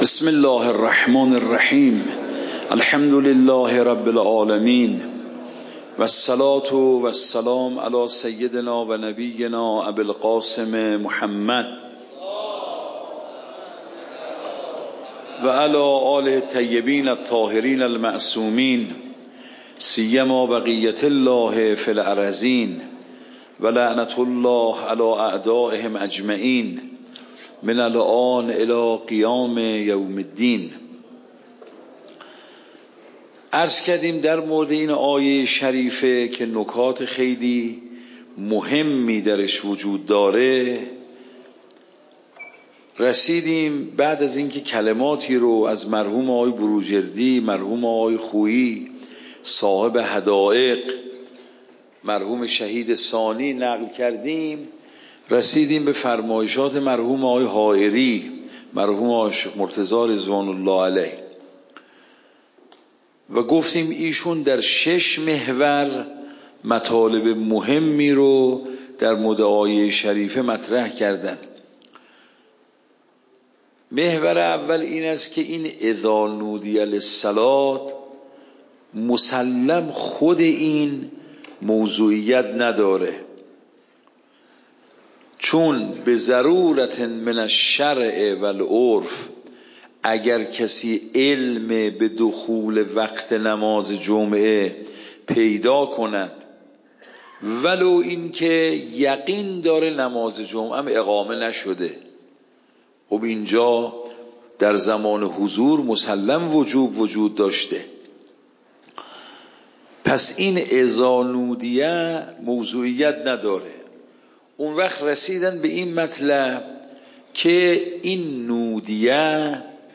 فقه خارج فقه
صوت درس